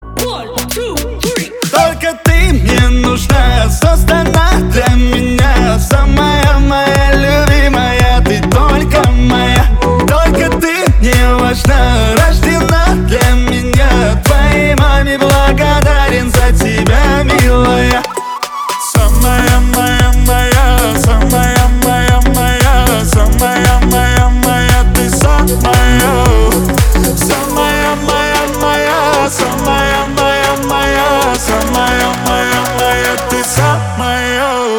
• Качество: 320, Stereo
поп
восточные мотивы
веселые
Dance Pop